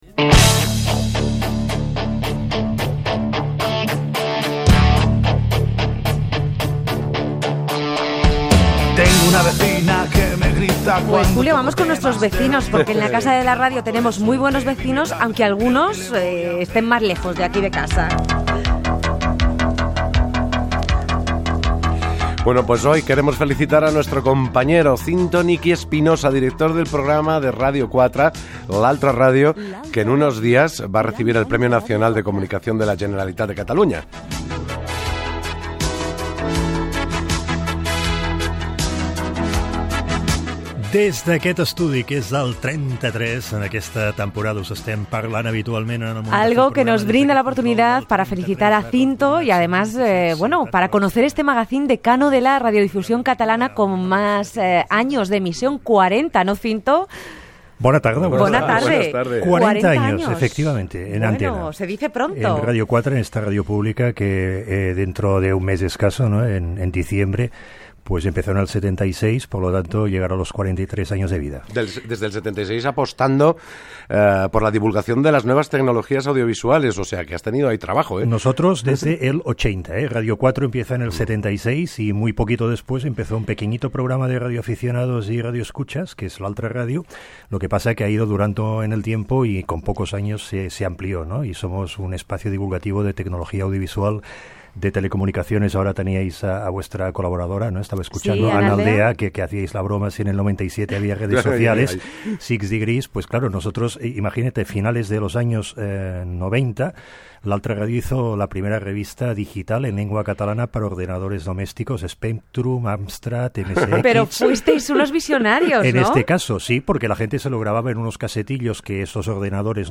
Entrevista
FM